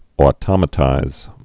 (ô-tŏmə-tīz)